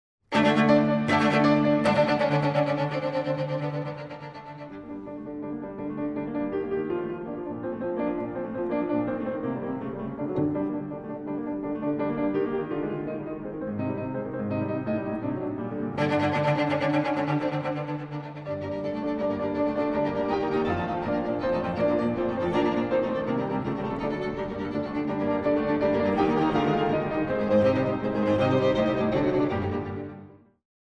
Piano Trio in G minor